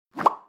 bubble_pop_alt.wav